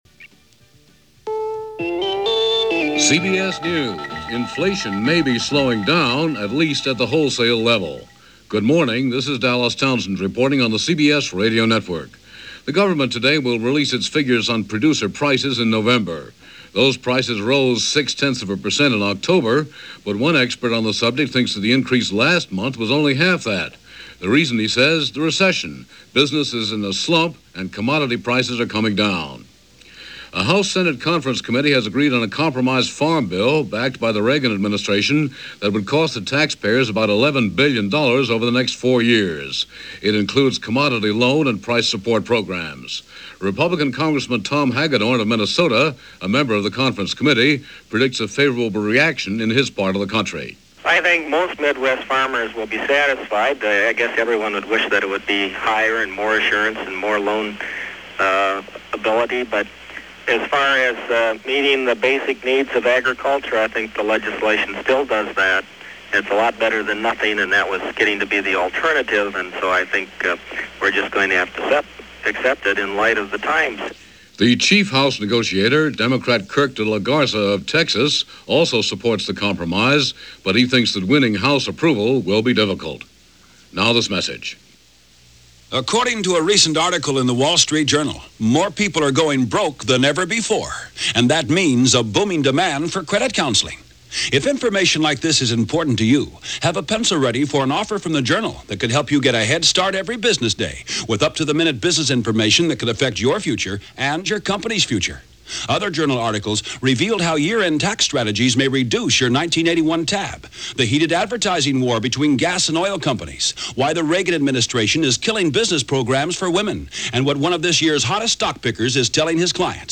CBS Radio News On The Hour